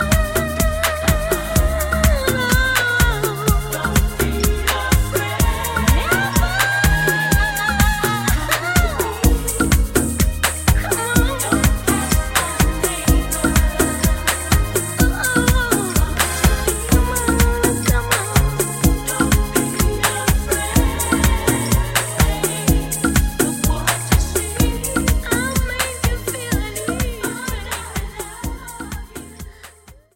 125 Voc